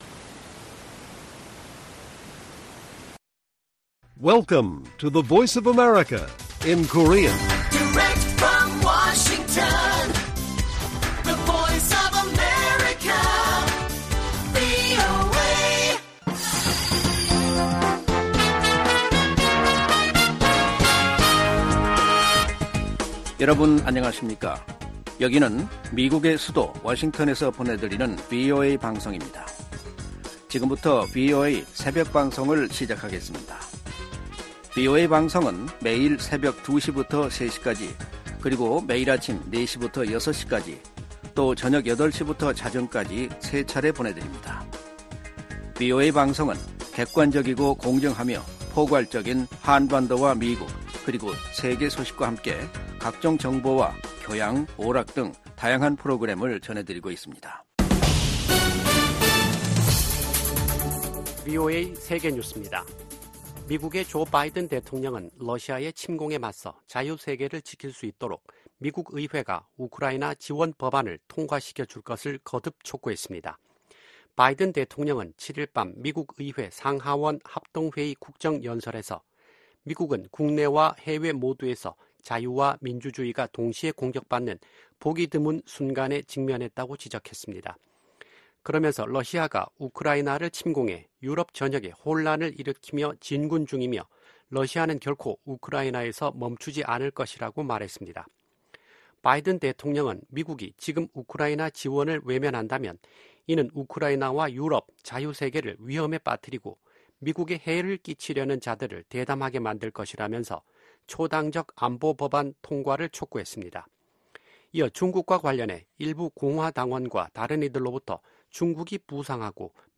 VOA 한국어 '출발 뉴스 쇼', 2024년 3월 9일 방송입니다. 조 바이든 미국 대통령이 국정연설에서 자유 세계를 지키기 위해 우크라이나를 침공한 러시아를 막아야 한다고 강조했습니다. '프리덤실드' 미한 연합훈련이 진행 중인 가운데 김정은 북한 국무위원장이 서울 겨냥 포사격 훈련을 지도했습니다.